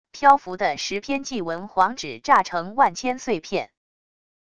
漂浮的十篇祭文黄纸炸成万千碎片wav音频